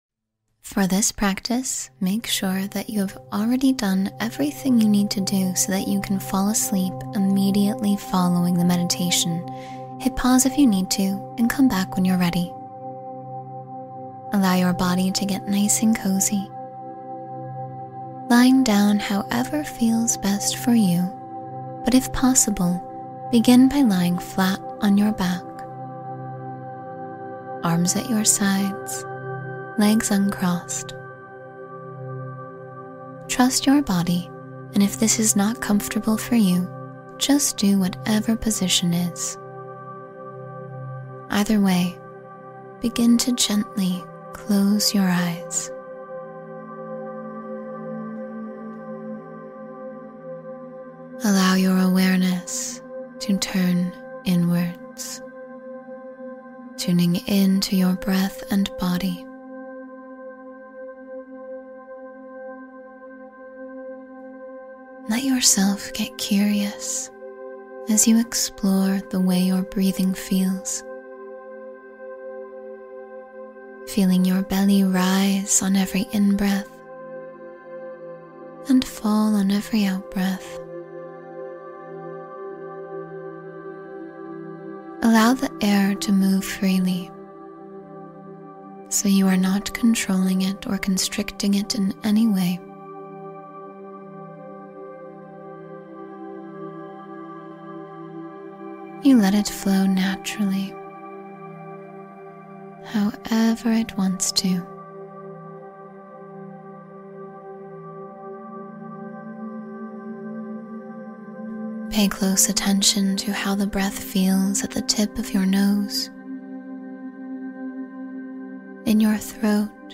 Rest and Heal with Deep Sleep Energy — Guided Meditation for Restorative Sleep